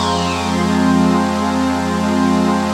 ATMOPAD29.wav